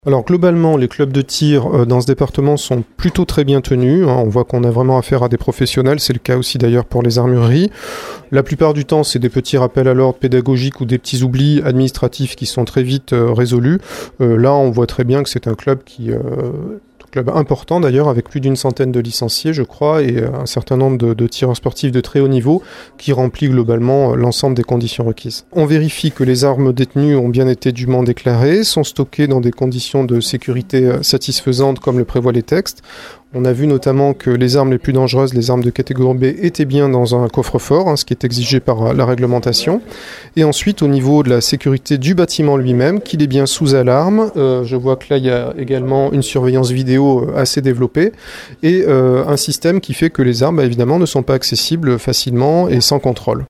On écoute le sous-préfet de Jonzac Jérôme Aymard :